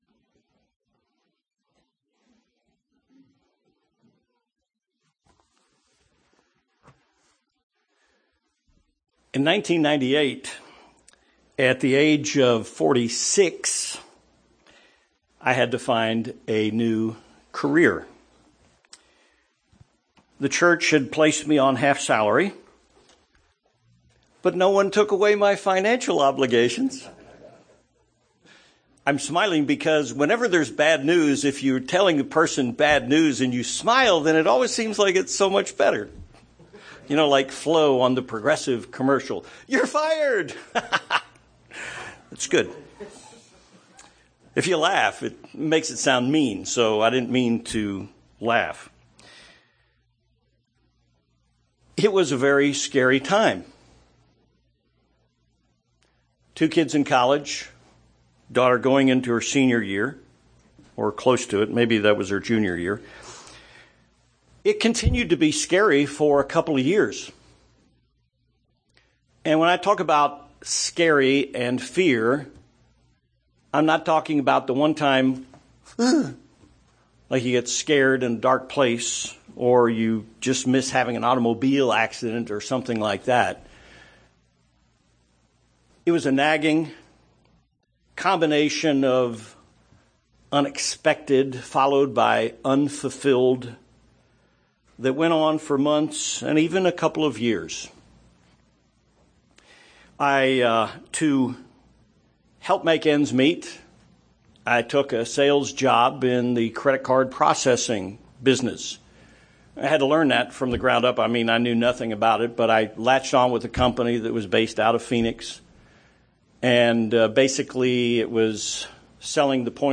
Sermon
Given in El Paso, TX Tucson, AZ